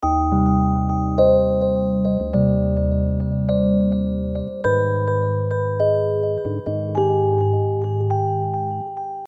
描述：罗德，延迟，奇怪。
Tag: 104 bpm Chill Out Loops Organ Loops 1.55 MB wav Key : Unknown